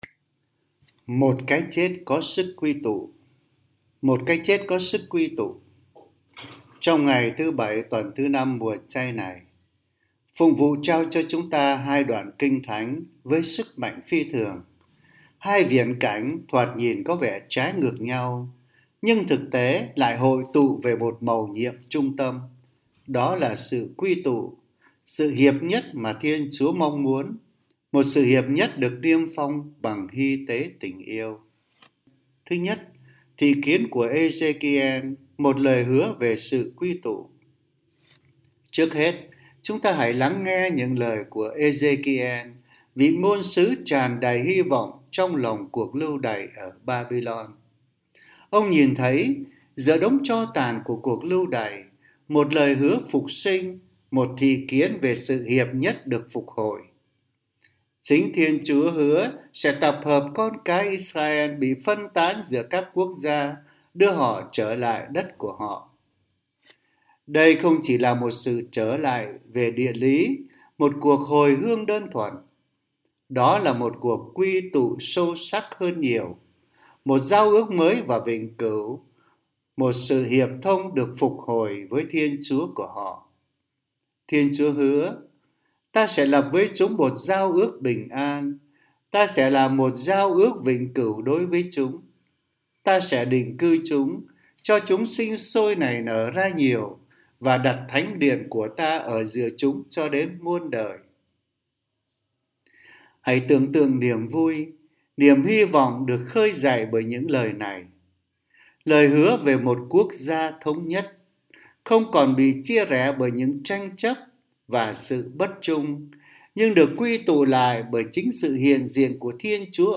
Suy niệm hằng ngày
🎙 Nữ miền Nam
🎙 Nam miền Nam